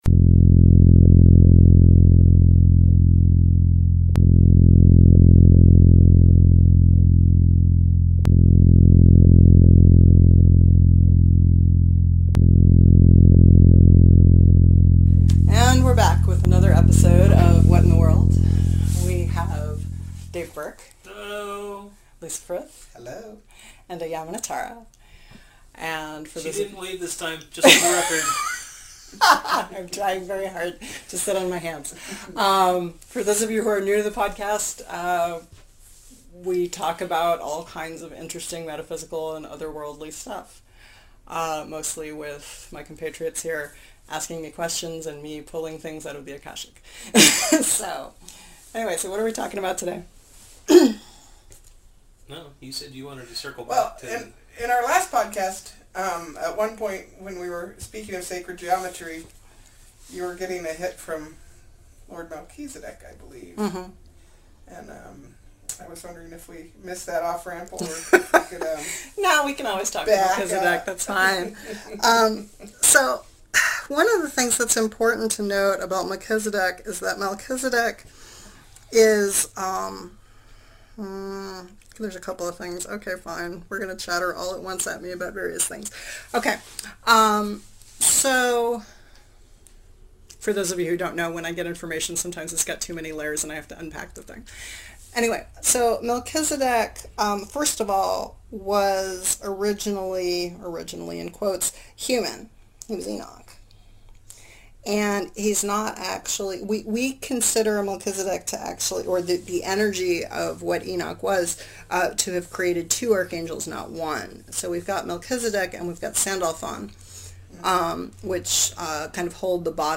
Intro and Outro are a recording of a Keppler star, courtesy of NASA